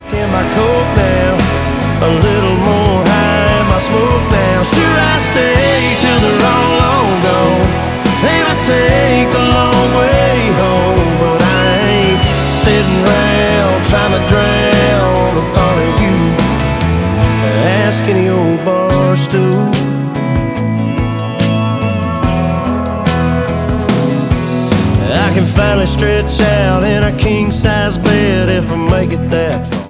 • Country